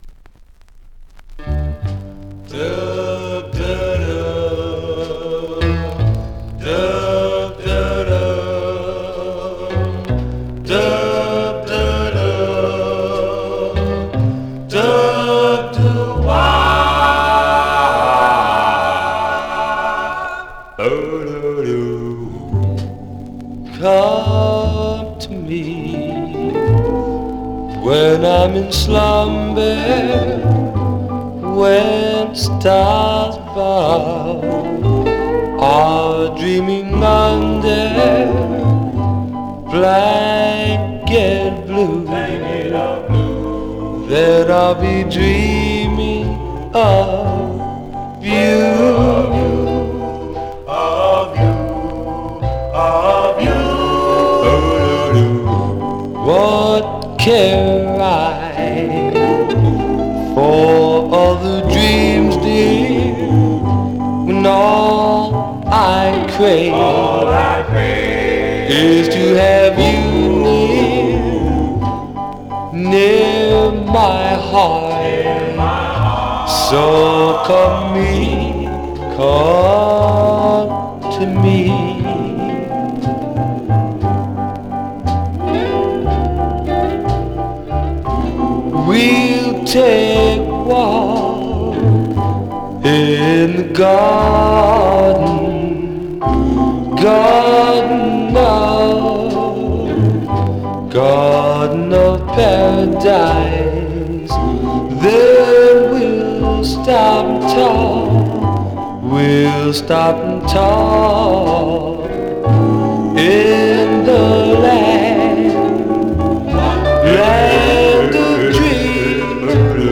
Some surface noise/wear
Mono
Male Black Groups